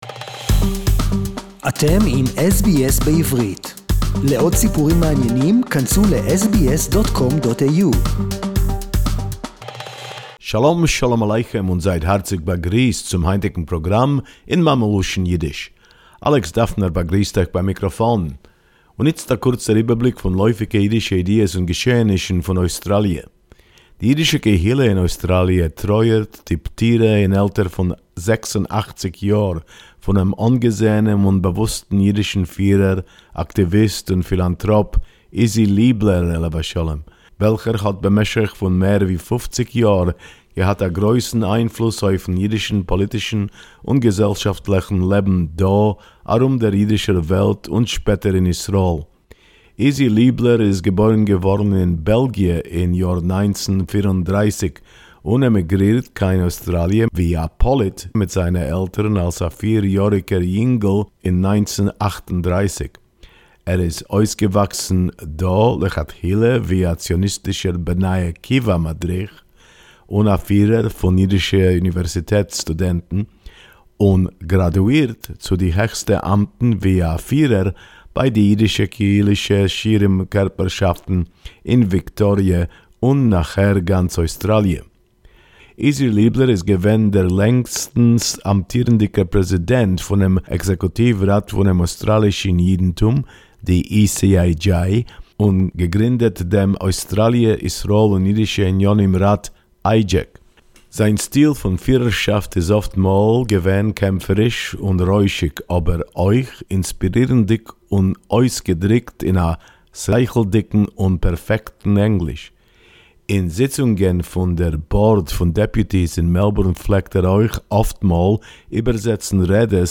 SBS Yiddish report 18.4.2021